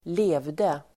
Uttal: [²l'e:vde]